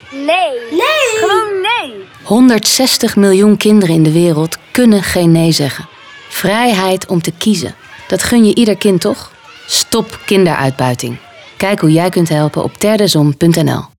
Beluister hier de radio commercial Kinderen staan centraal in alles wat we doen Miljoenen kinderen wereldwijd worden seksueel uitgebuit of gedwongen te werken.